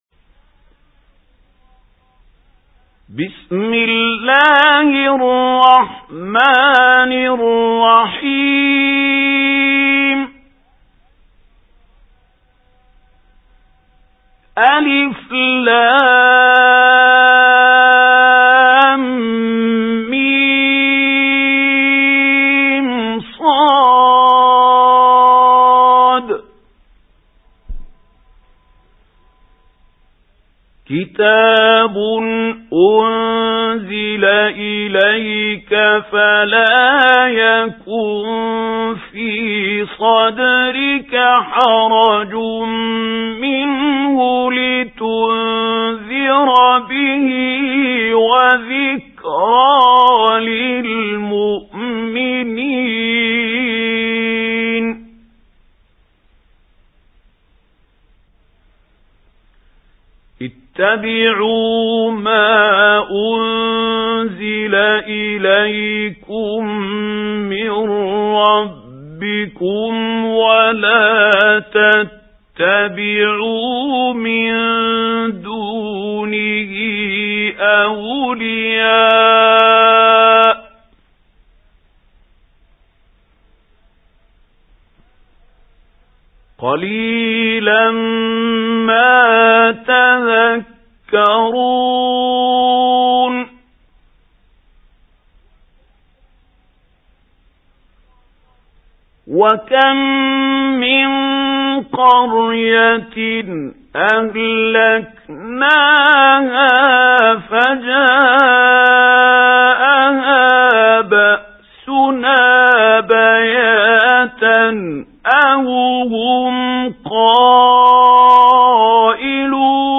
سُورَةُ الأَعۡرَافِ بصوت الشيخ محمود خليل الحصري